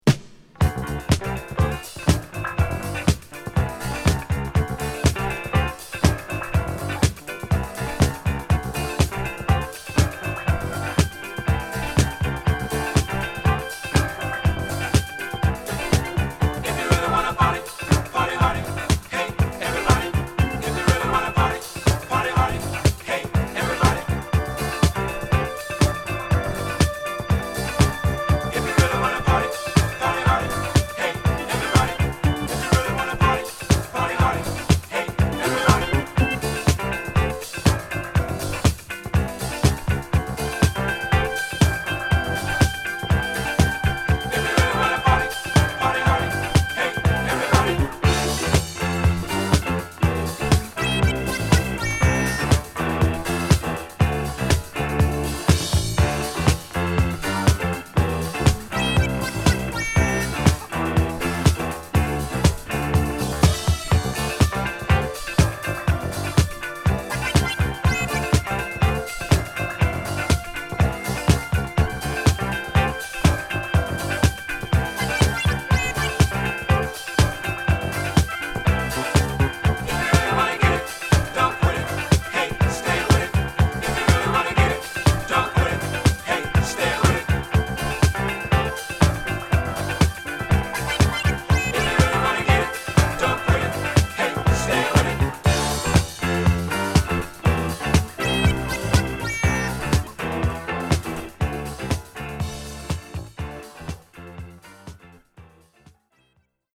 ナイス・ディスコ45！